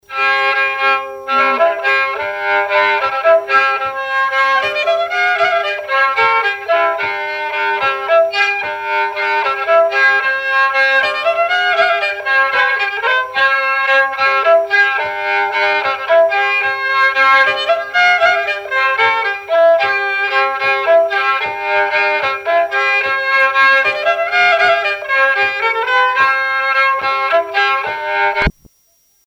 Valse anglaise
répertoire folk
danse : valse anglaise
Pièce musicale inédite